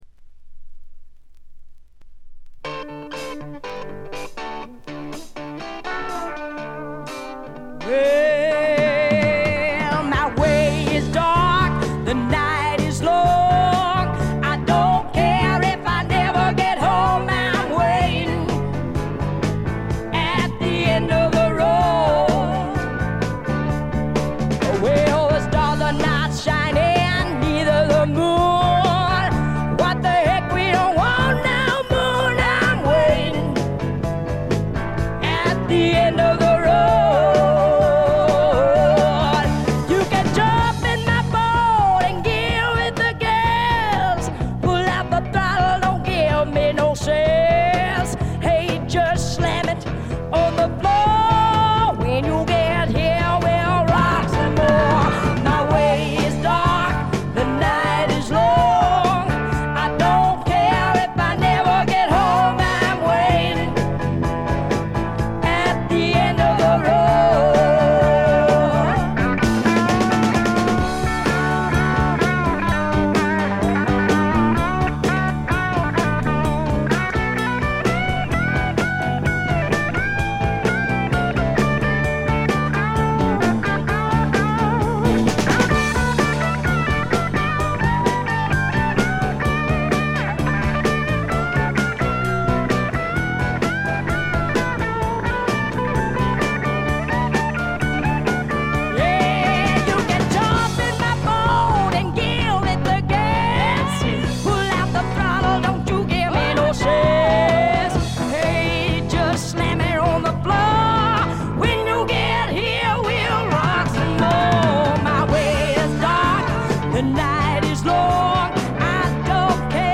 ほとんどノイズ感無し。
超重量級スワンプ名作。
試聴曲は現品からの取り込み音源です。